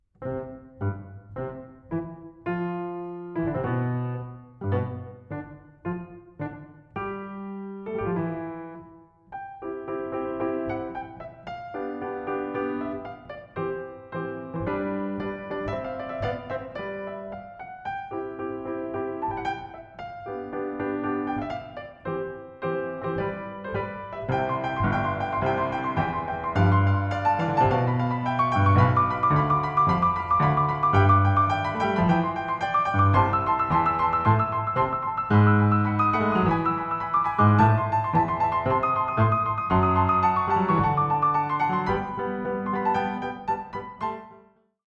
Klavier-Arrangement des Orchesterparts